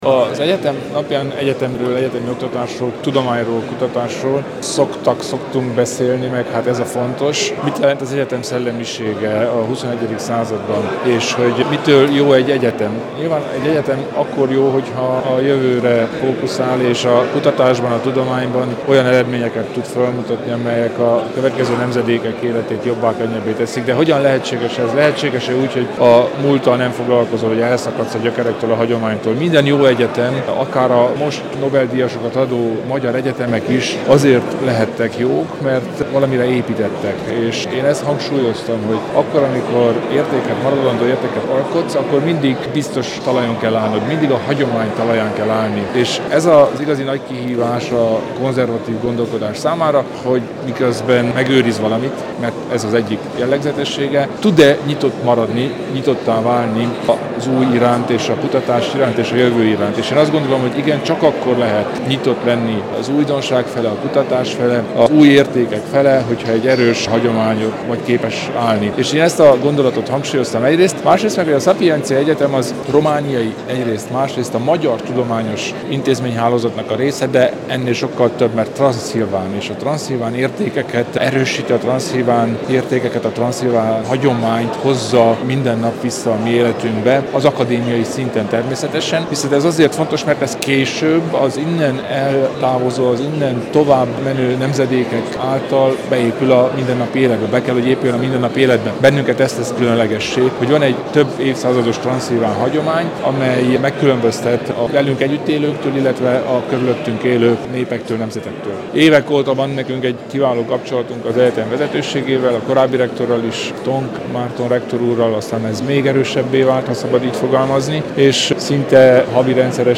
Az immáron hagyományosnak számító ünnepséget idén október 7-én, szombaton tartották.
Az erdélyi magyarság fennmaradásának kulcsa a tudásban is keresendő, ebben kínál segítséget a Sapientia, ami mostanra szerves része a romániai felsőoktatási rendszernek, mondta Kelemen Hunor, az RMDSZ elnöke, aki azt is hozzátette, hogy a Sapientia javaslatait a felsőoktatási törvény kidolgozásakor is figyelembe vették és hasznosították.